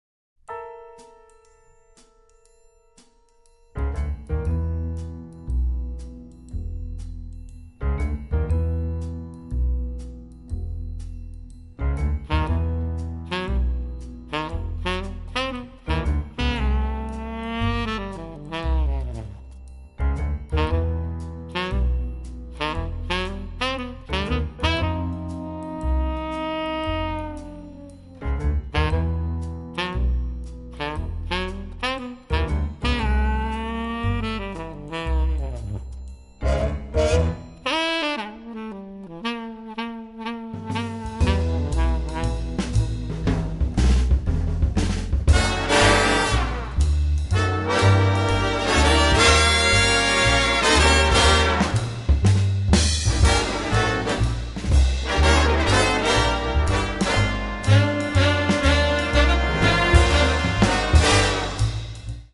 Recorded at Todd A-O and Sony Pictures Scoring Stages